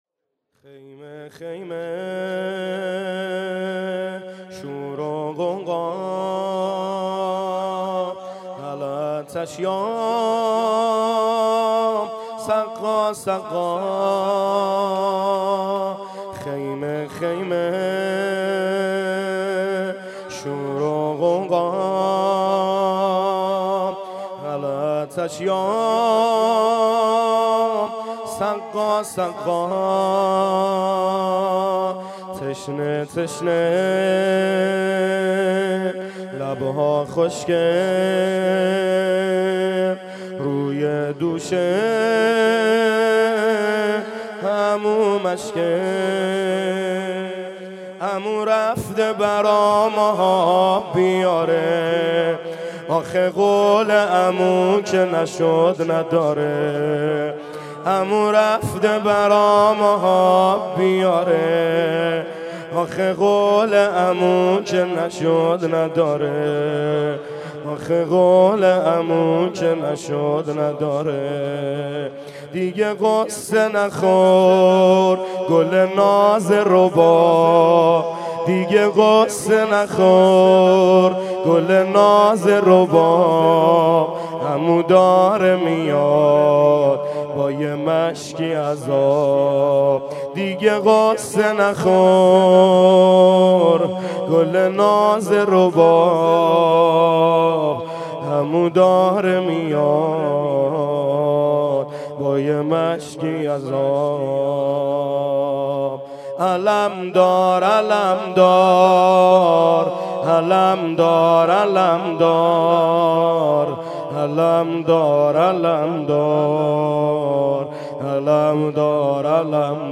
زمینه | عمو رفته آب بیاره
گزارش صوتی شب نهم (تاسوعا) محرم 96 | هیأت محبان حضرت زهرا سلام الله علیها زاهدان